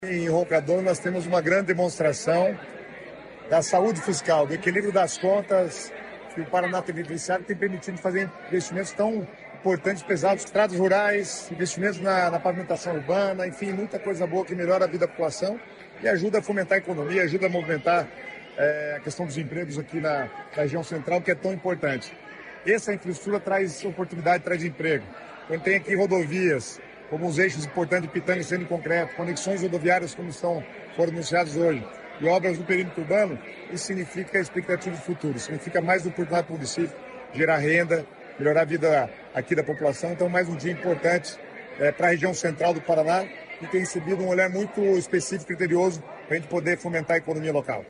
Sonora do secretário de Estado das Cidades, Guto Silva, sobre os investimentos em Roncador